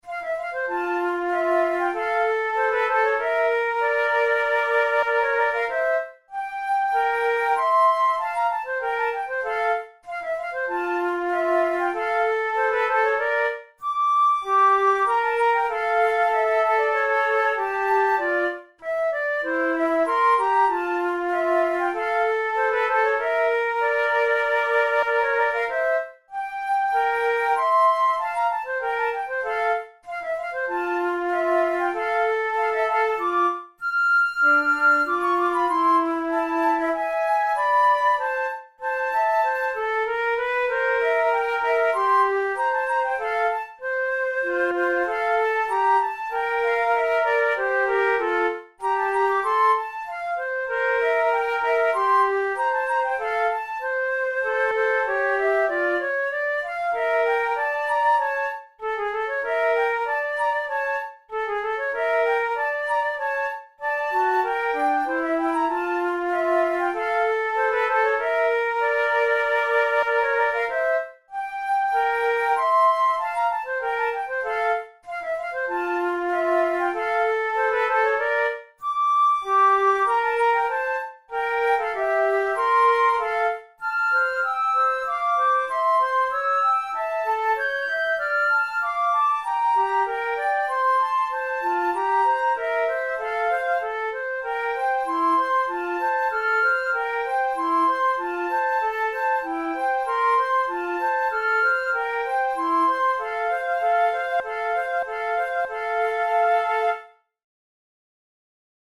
The upper part is very melodic, while the lower part is more of an accompaniment.
Categories: Romantic Written for Flute Difficulty: easy
kohler-op93-no1-duet-no4-in-f-major.mp3